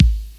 Kick
Original creative-commons licensed sounds for DJ's and music producers, recorded with high quality studio microphones.
Rich Mid-Range Steel Kick Drum Sample A Key 68.wav
prominent-bass-drum-a-key-688-20w.wav